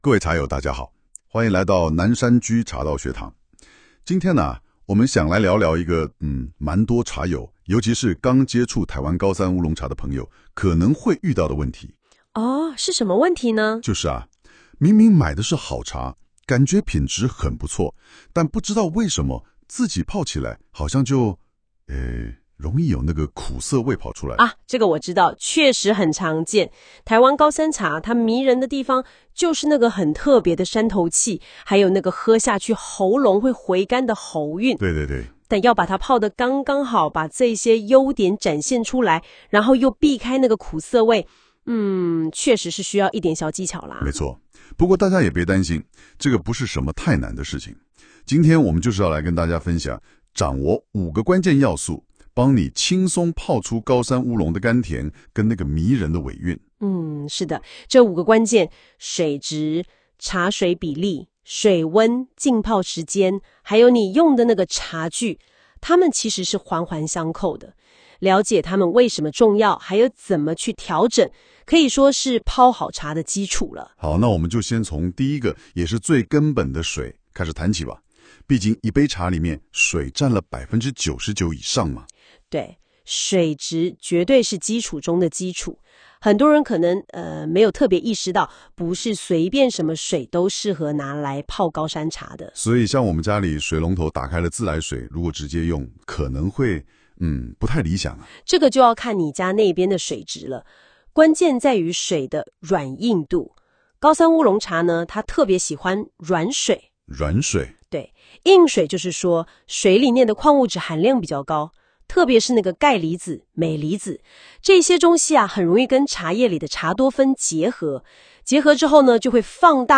【語音導讀】